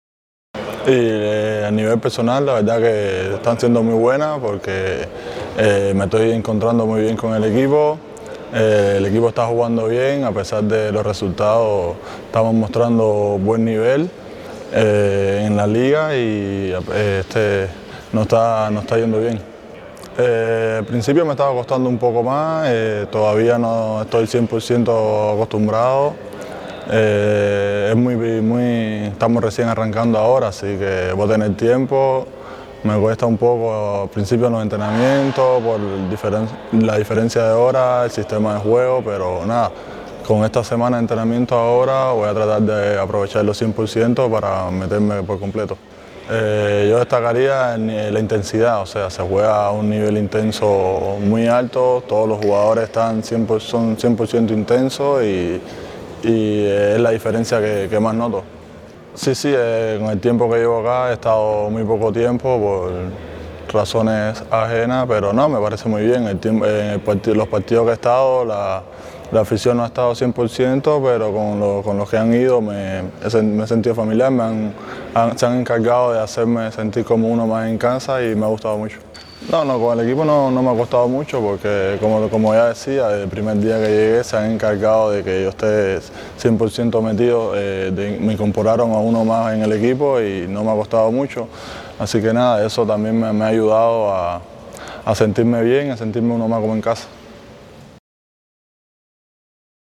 Entrevista: